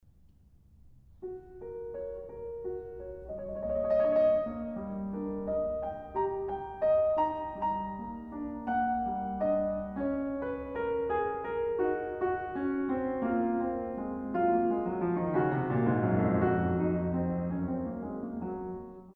in F-Sharp Major